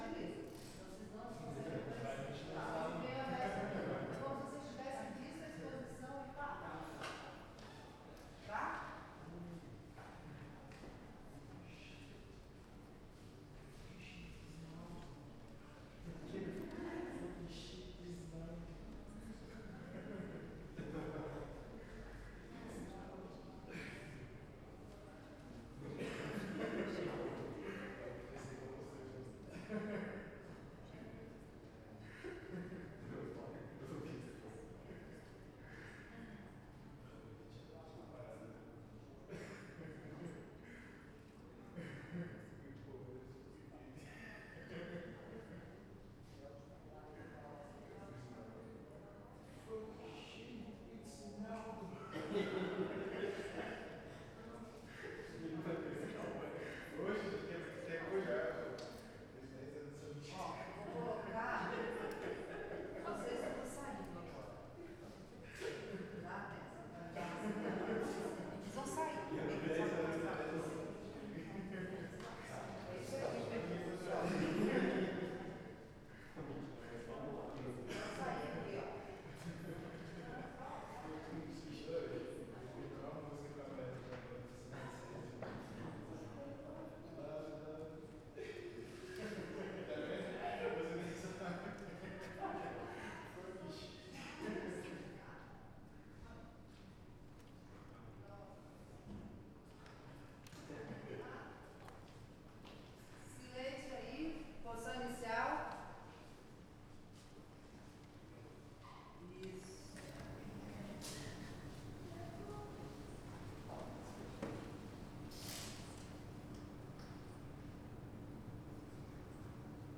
Pessoas na parte externa da galeria de arte da Universidade de Brasilia Cochicho , Galeria , Murmurinho , Pessoas
Stereo
CSC-04-041-GV - Murmurinho de Pessoas em Galeria de Arte com Pe Direito Alto.wav